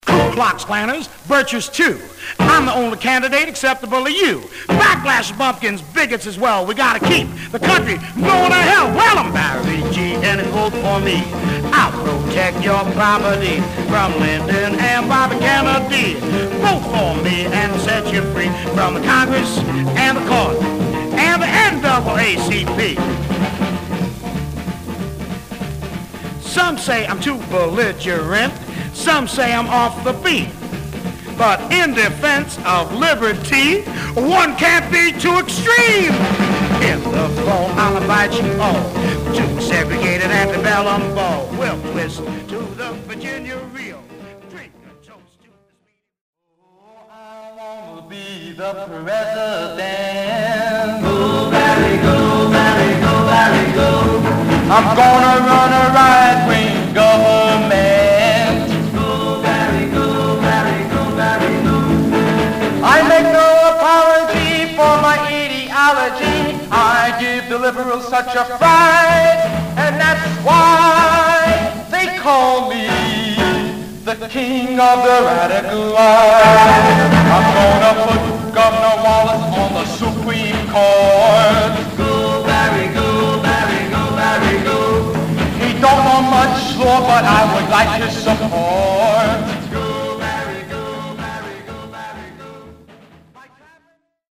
Some surface noise/wear
Mono
Male Black Group
Novelty